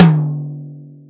• High Tom Drum Single Hit D Key 20.wav
Royality free tom tuned to the D note. Loudest frequency: 587Hz
high-tom-drum-single-hit-d-key-20-0vN.wav